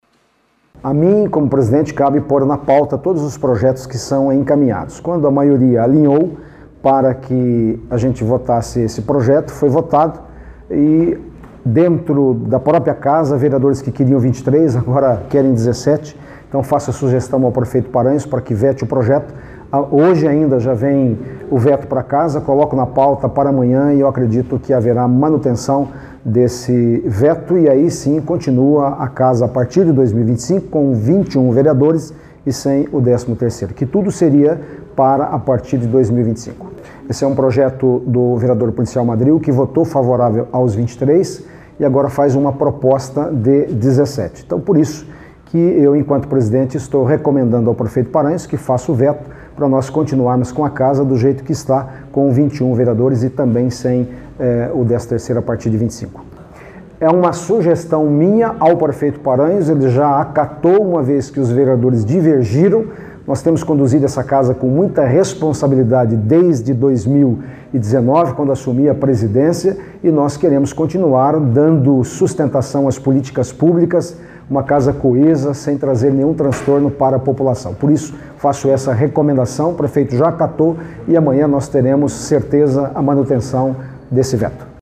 Hoje o presidente da Câmara Alécio Espínola falou sobre a sugestão ao prefeito para vetar o projeto.